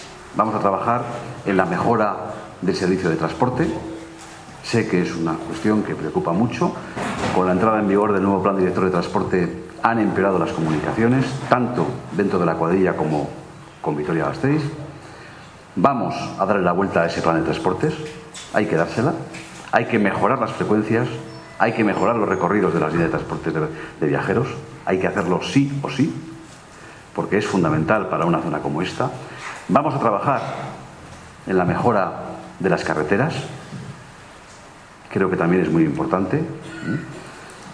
Ramiro González en la presentación de las candidaturas municipales en la cuadrilla de Añana (2)